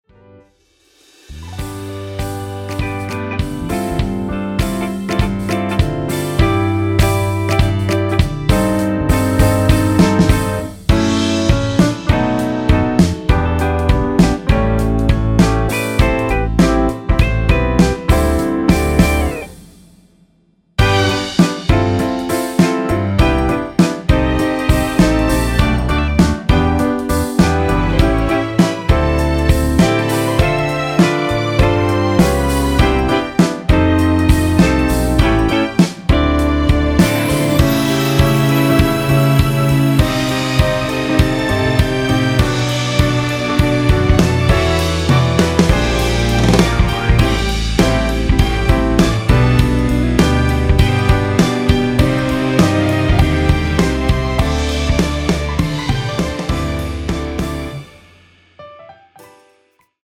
원키에서(+4)올린 (1절+후렴)MR입니다.
앞부분30초, 뒷부분30초씩 편집해서 올려 드리고 있습니다.
중간에 음이 끈어지고 다시 나오는 이유는